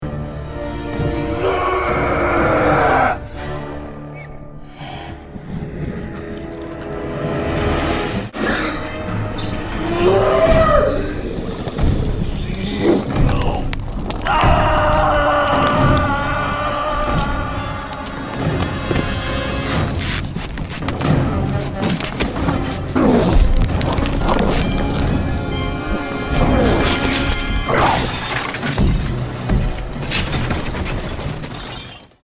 Звуки Халка
Скачивайте или слушайте онлайн его легендарный рык, яростные крики, разрушительные удары и угрожающее дыхание.